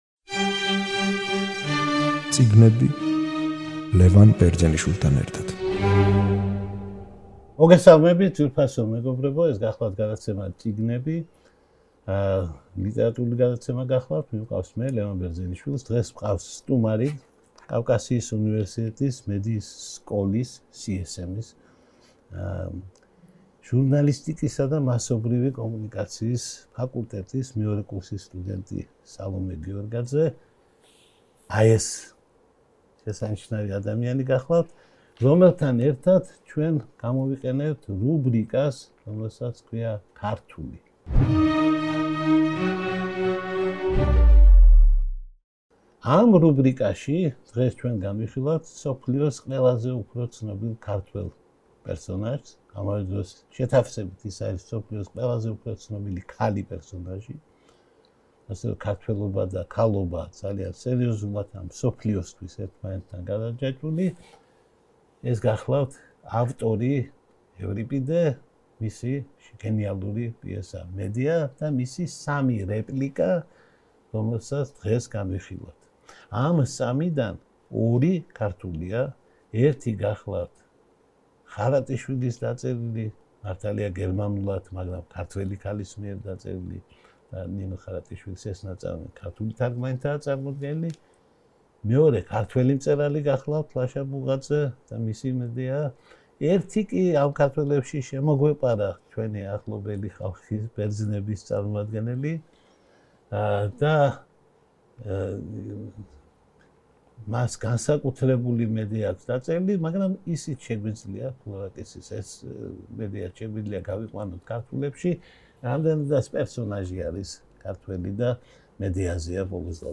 გადაცემა „წიგნები“ დღეს მიმართავს თავის რუბრიკას „ქართულს“. გადაცემაში მოვიწვიეთ სტუმარი